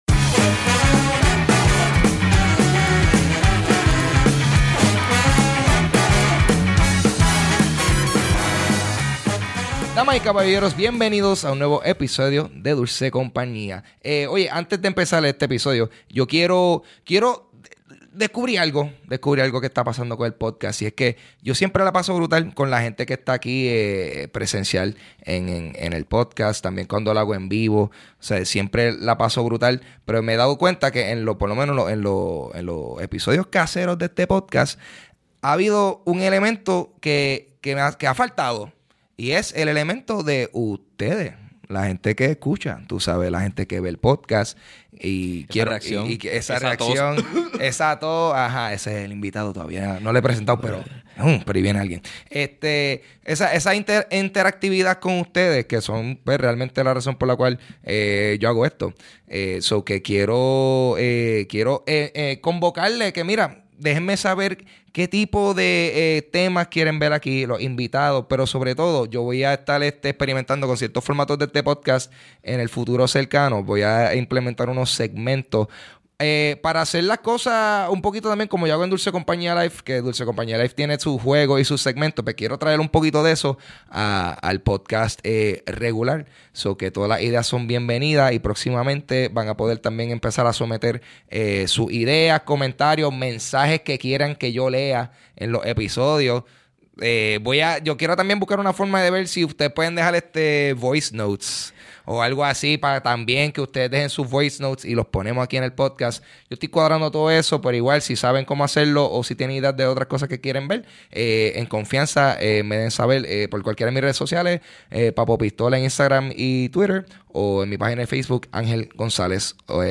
Hablo con el comediante/podcastero